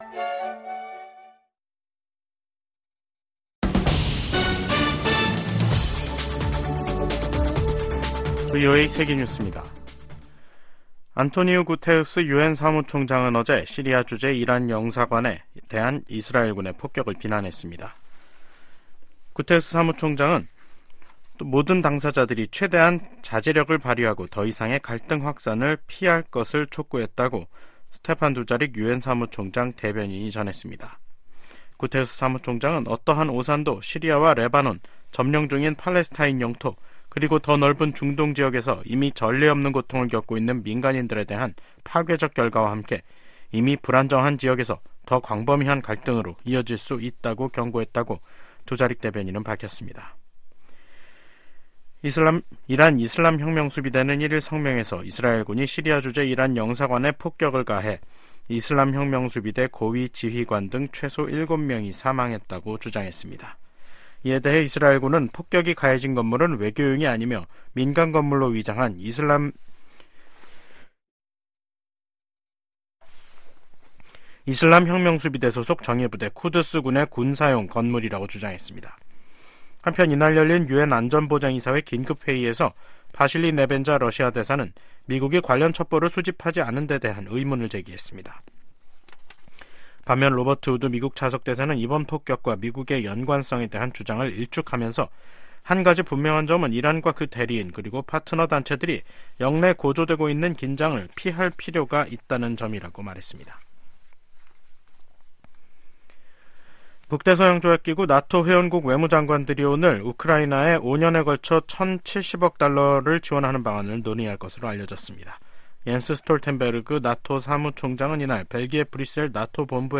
VOA 한국어 간판 뉴스 프로그램 '뉴스 투데이', 2024년 4월 3일 2부 방송입니다. 북한이 신형 중장거리 고체연료 극초음속 탄도미사일 시험발사에 성공했다고 대외 관영매체들이 보도했습니다. 북한이 보름 만에 미사일 도발을 재개한 데 대해 유엔은 국제법 위반이라고 지적했습니다.